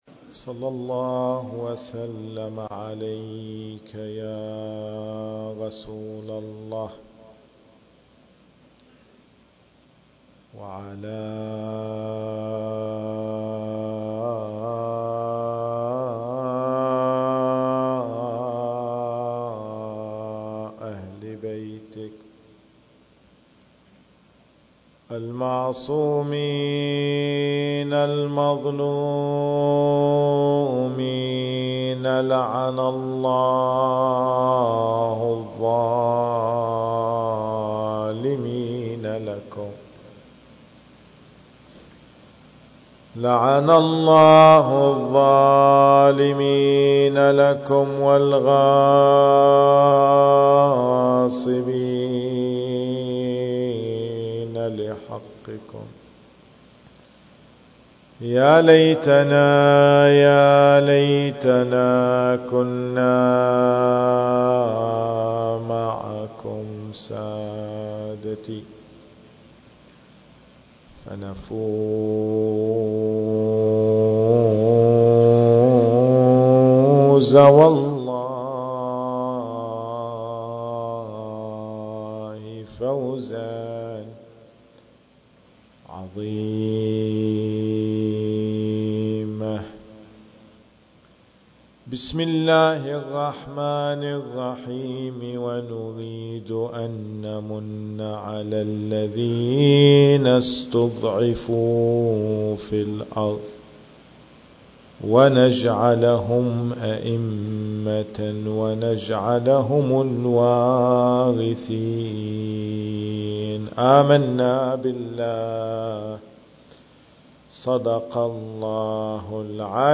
المكان: مأتم الزهراء (عليها السلام) / صفوى التاريخ: 10 محرم الحرام/ 1441 للهجرة